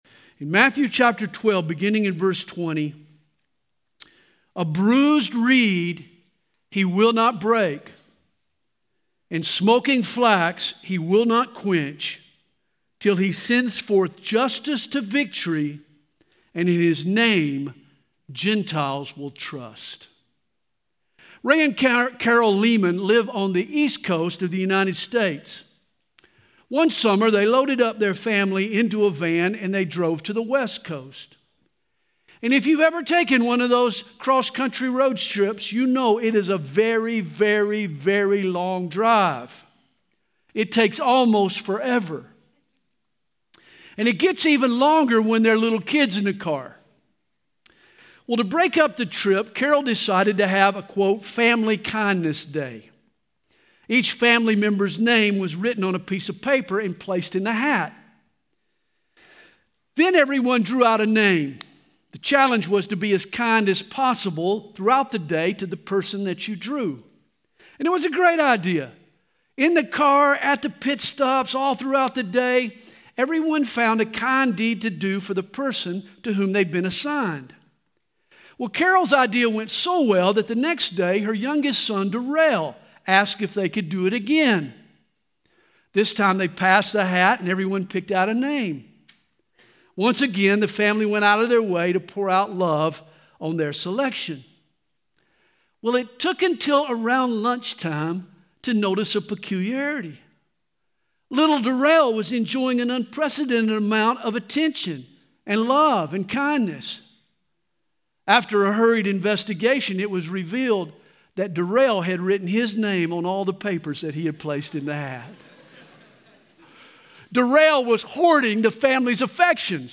Home » Sermons » A Splint and a Flint
Conference: Youth Conference